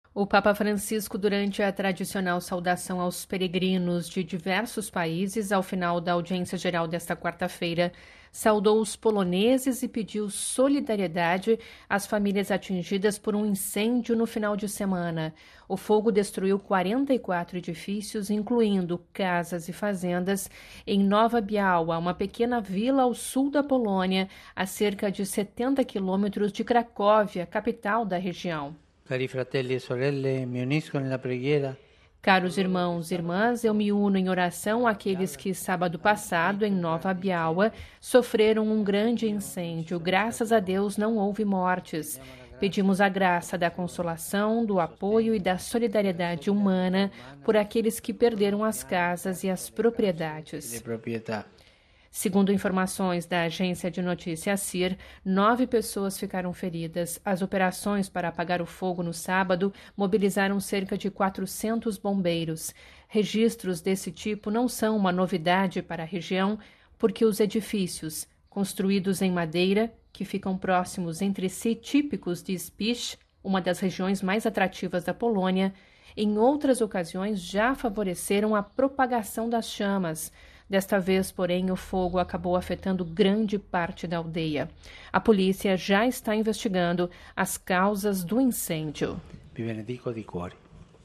Ouça a reportagem com a voz do Papa e compartilhe
O Papa Francisco, durante a tradicional saudação aos peregrinos de diversos países ao final da Audiência Geral desta quarta-feira (23), saudou os poloneses e pediu solidariedade às famílias atingidas por um incêndio no final de semana.